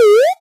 bubble.ogg